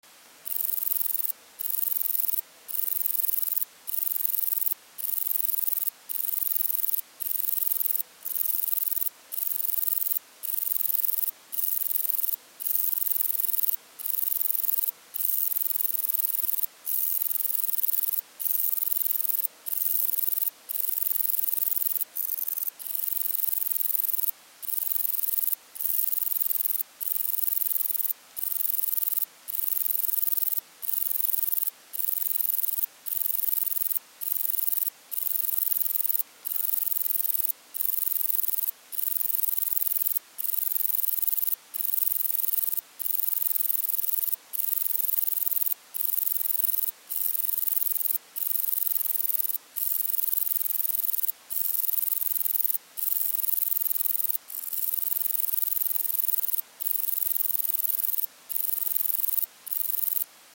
Metrioptera (Bicolorana) bicolor Phil. -
Скачок двуцветный показать фото показать фото показать фото показать фото показать фото показать фото другие фото звуковой файл звуковой файл